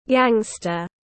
Côn đồ xã hội đen tiếng anh gọi là gangster, phiên âm tiếng anh đọc là /ˈɡæŋ.stər/.
Gangster /ˈɡæŋ.stər/